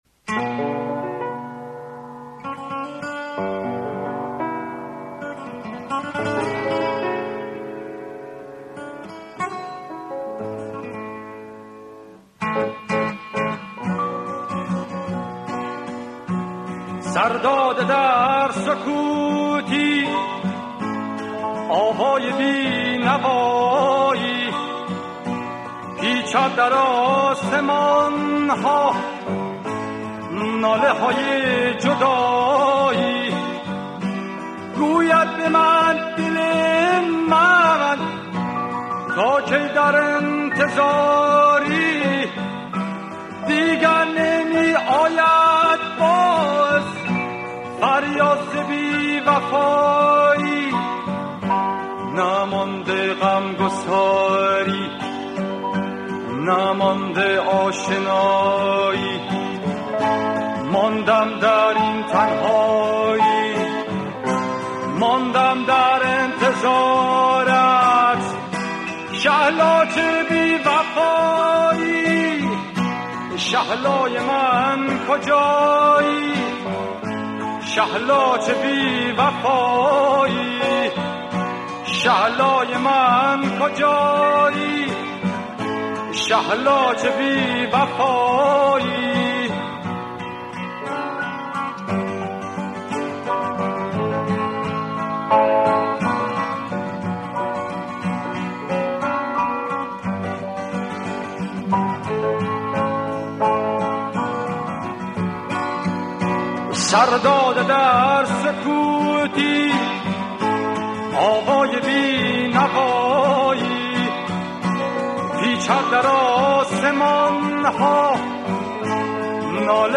گیتار ۱۲ سیمی
آهنگسازی متأثر از موسیقی راک غربی
موسیقی پاپ ایران